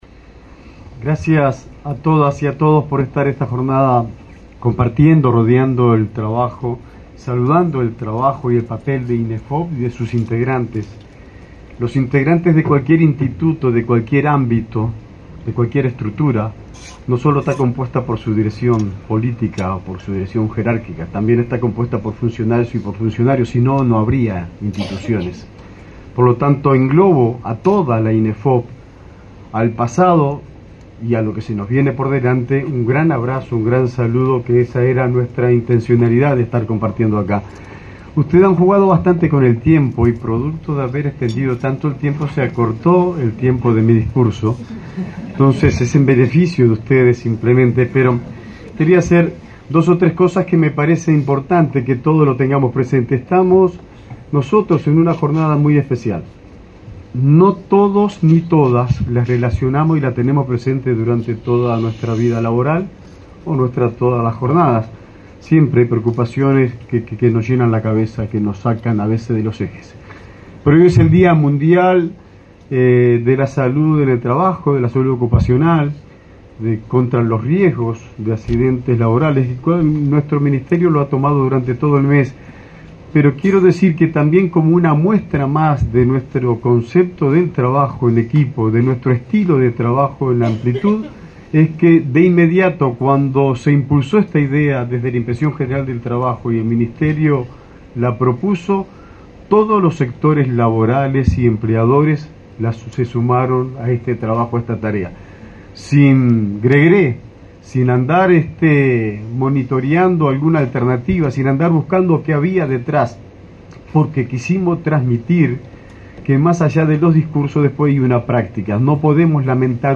Palabras del ministro de Trabajo, Juan Castillo
El ministro de Trabajo, Juan Castillo, participó, este lunes 28, en el acto de asunción del nuevo Consejo Directivo del Instituto Nacional de Empleo y